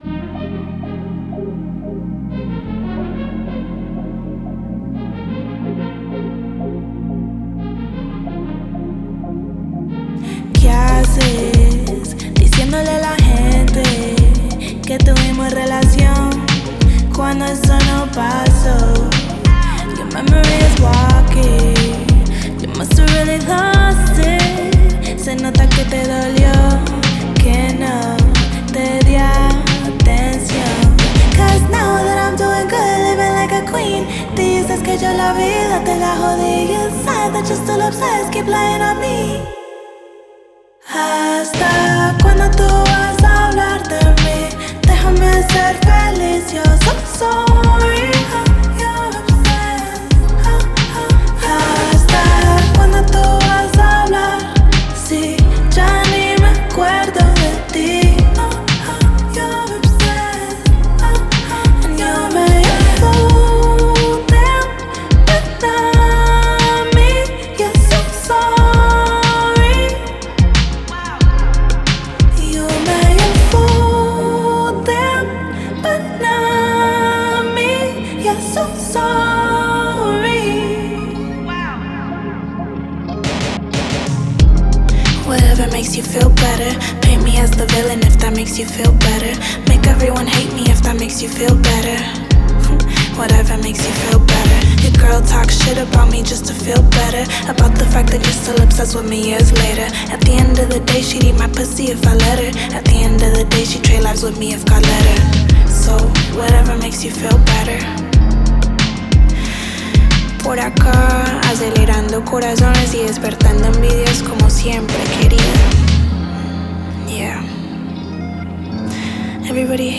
BPM91
Audio QualityPerfect (High Quality)